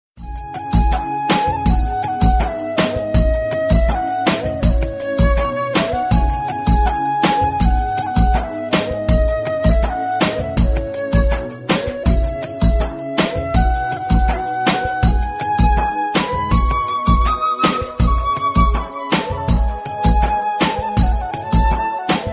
File Type : Latest bollywood ringtone